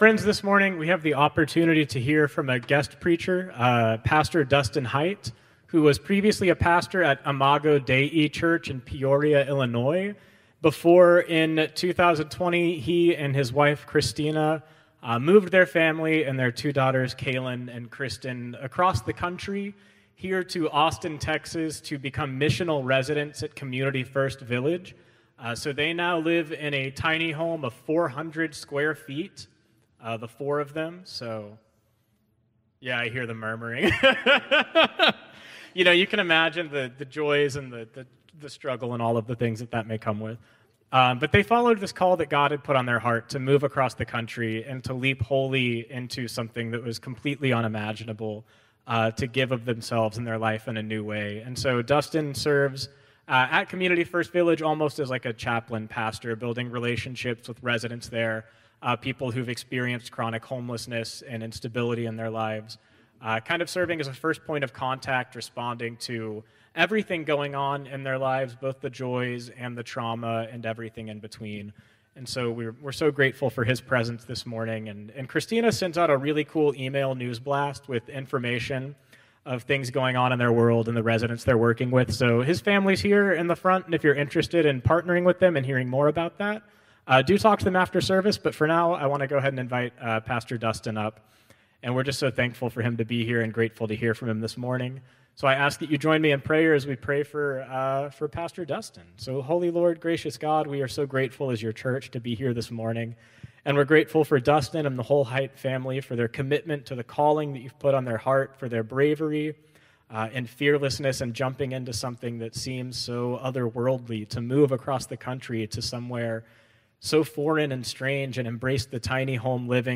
Traditional Service 6/1/2025